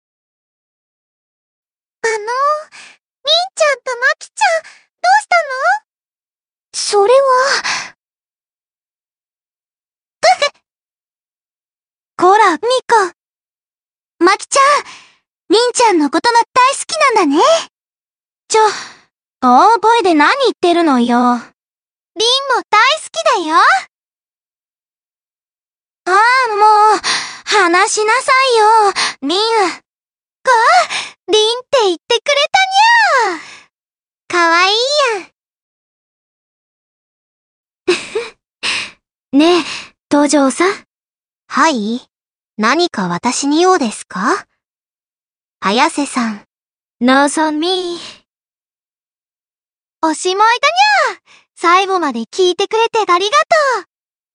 注意：過去のラ！板ｓｓで自分の好きなものを、最近はやりの中華ＡＩツールに読ませてみました。
暗い声色が少ないから悲しんでてもテンション高いな笑
音程の調整はできないんだよ